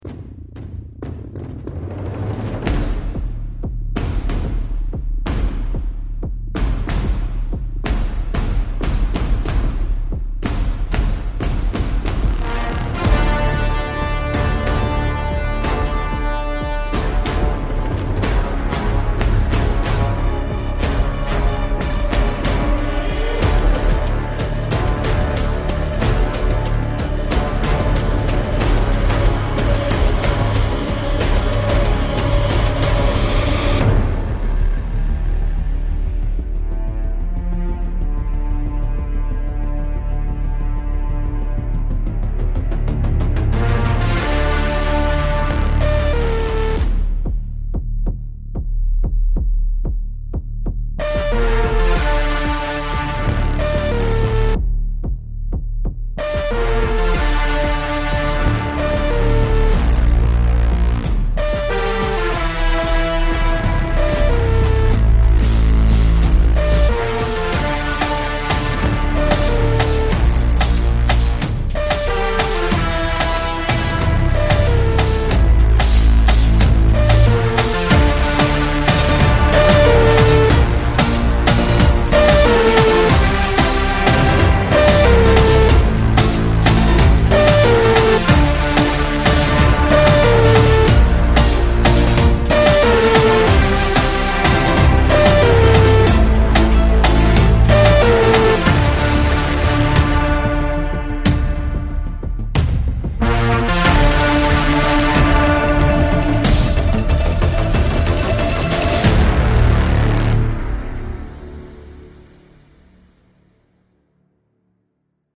Un bon score typé électro moche comme je les aime.